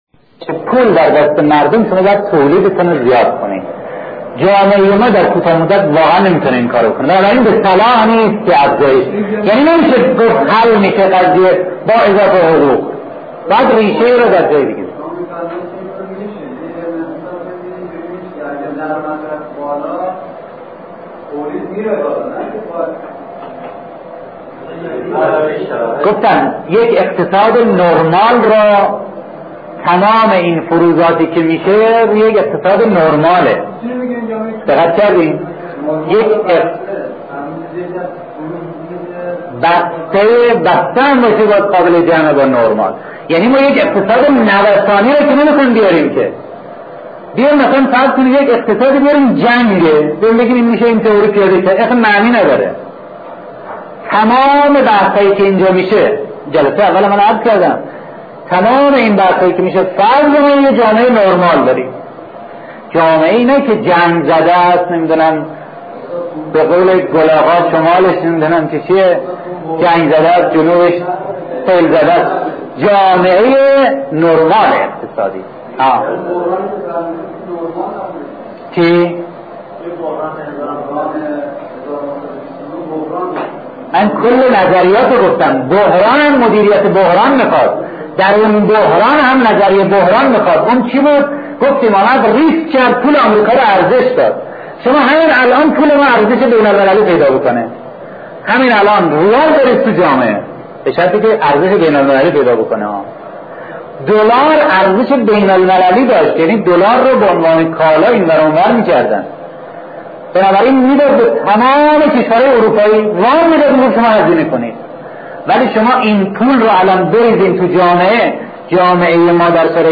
مباني علم اقتصاد | مرجع دانلود دروس صوتی حوزه علمیه دفتر تبلیغات اسلامی قم- بیان